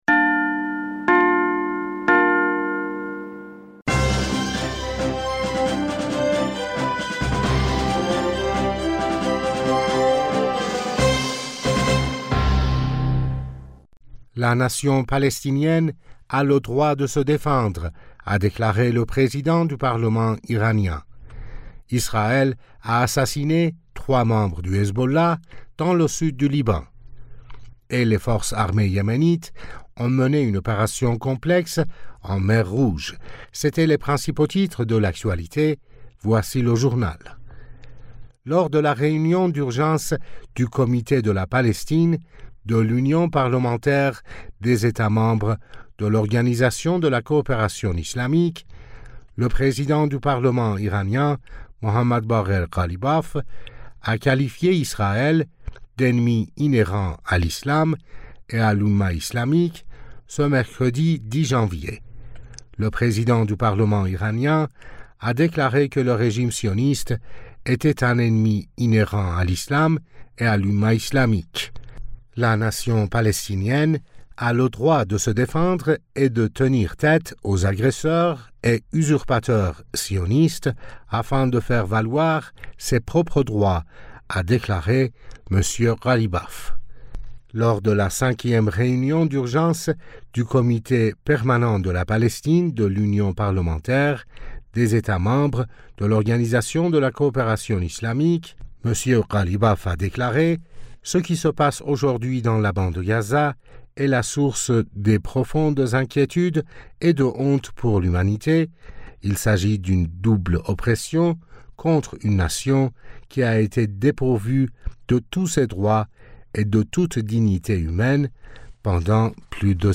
Bulletin d'information du 10 Janvier 2024